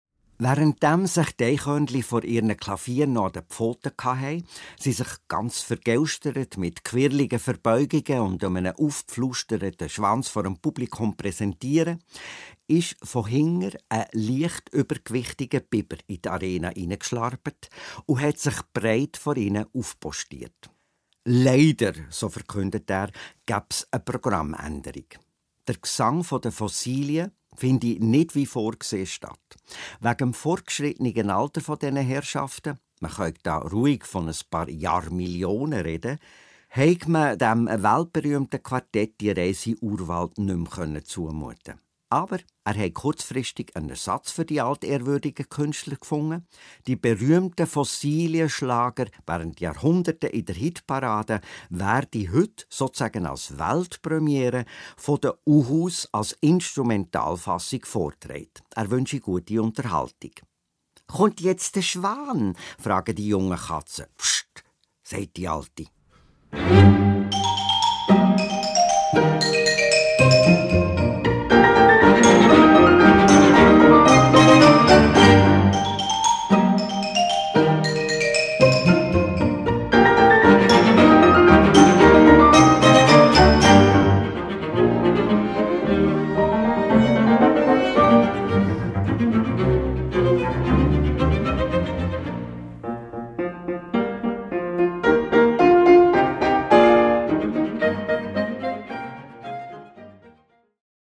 Allegro Ridicolo [ listen...